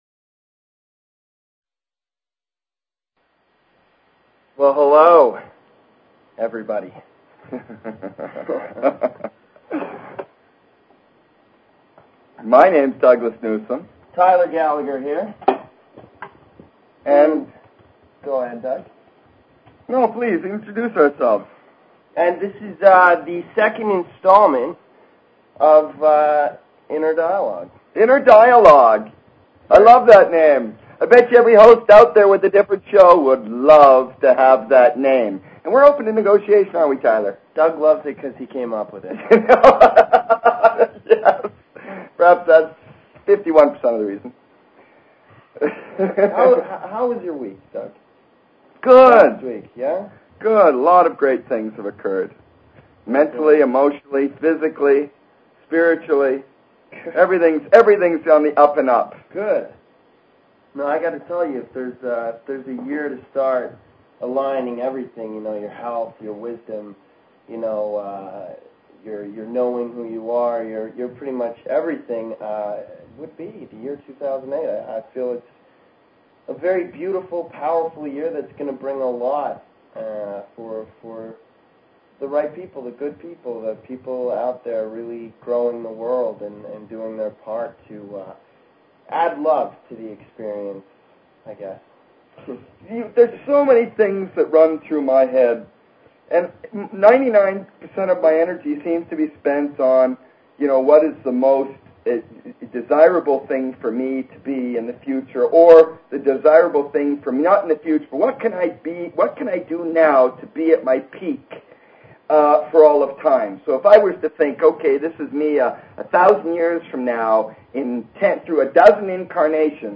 Talk Show Episode, Audio Podcast, Inner_Dialogue and Courtesy of BBS Radio on , show guests , about , categorized as